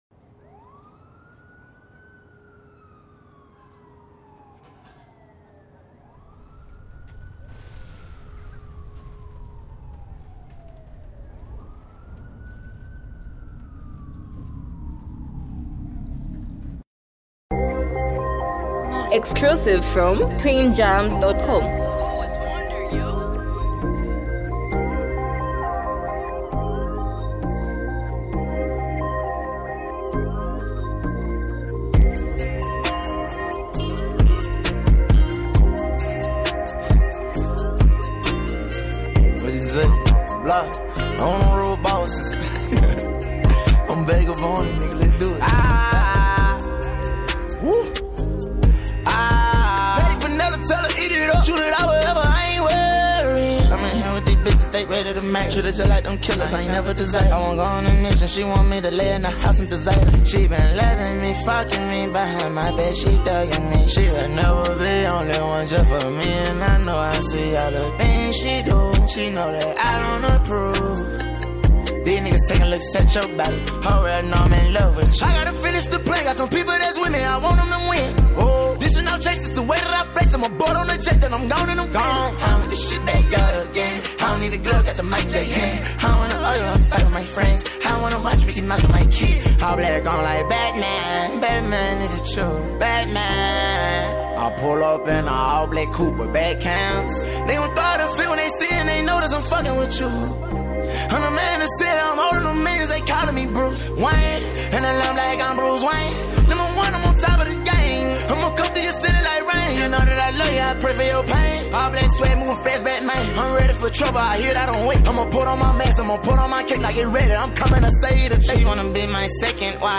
switching between aggressive bars and reflective melodies.